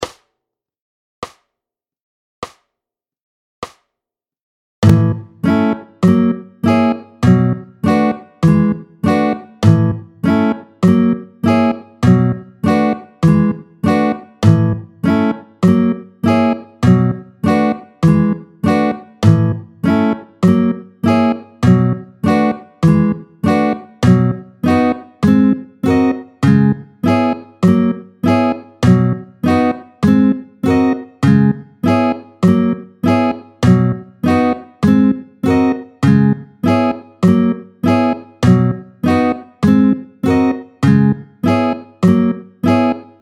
tempo 50
un peu plus swing cette fois